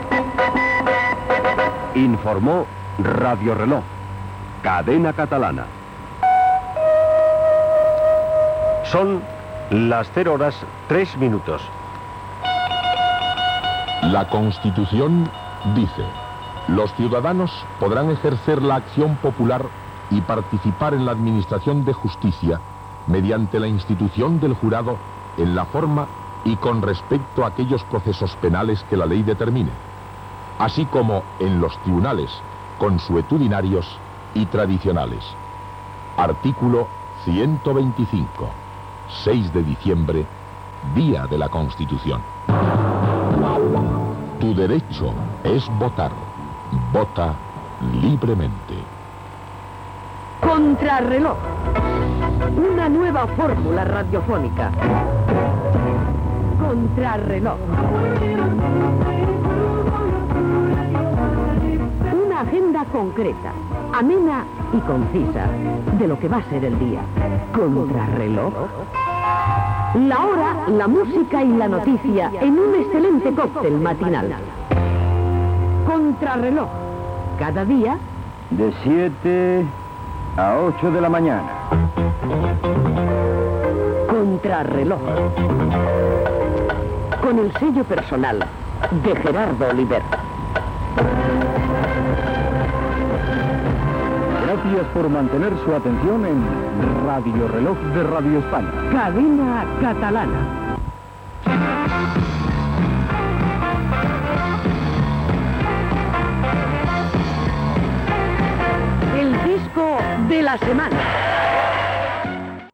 Careta de sortida de l'informatiu, indicatiu, fragment de la Constitució Espanyola, promoció "Contrareloj" i careta de "El disco de la semana".